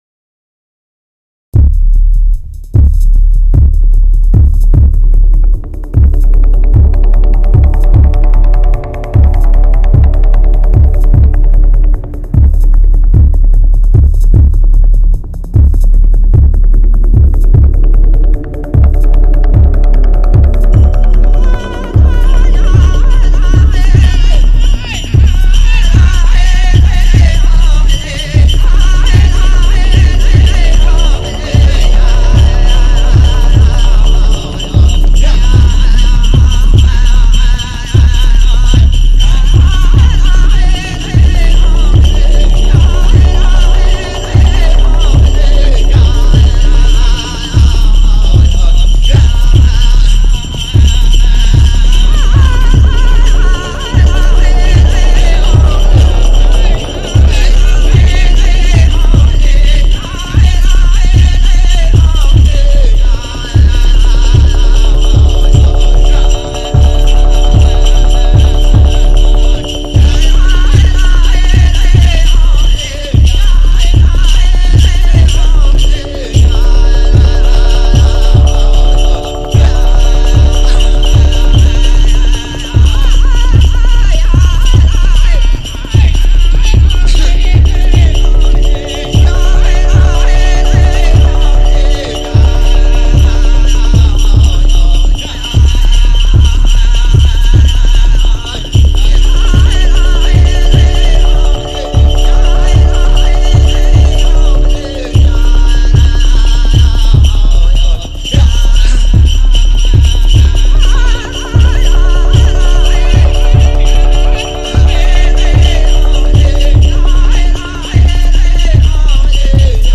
Genre: Native American.